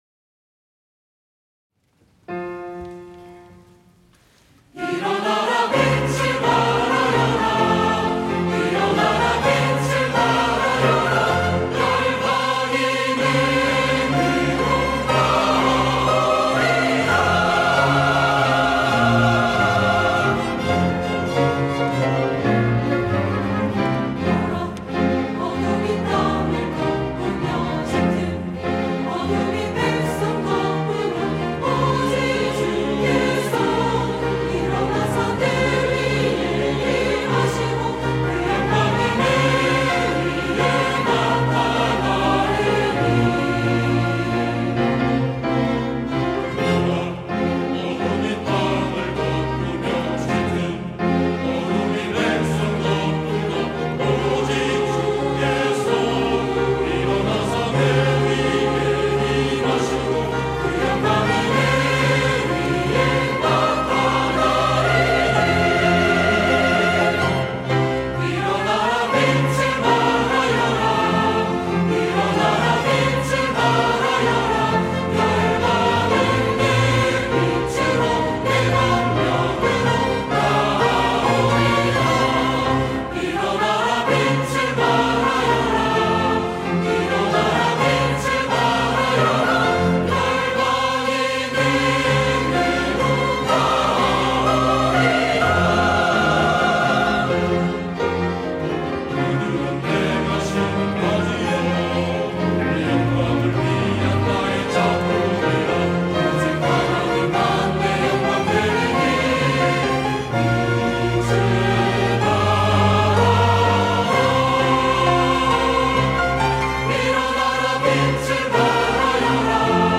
호산나(주일3부) - 일어나라 빛을 발하여라
찬양대